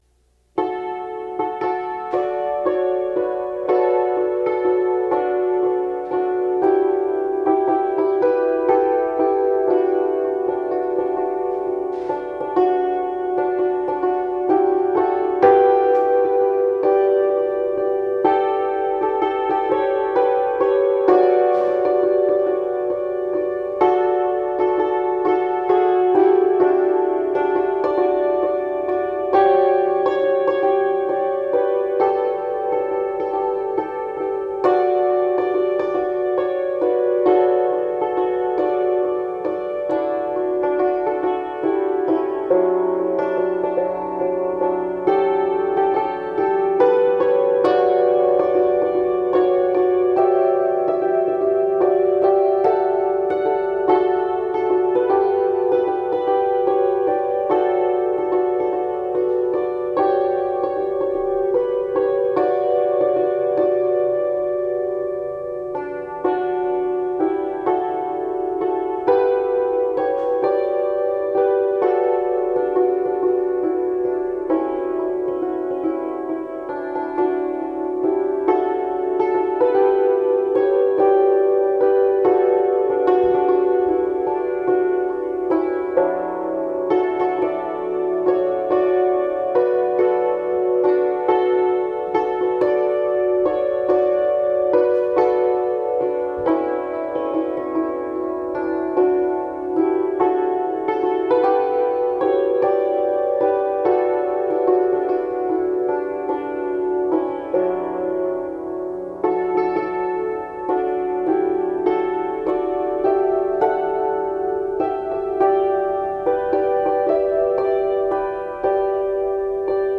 It was recorded in what was for many years the fair office.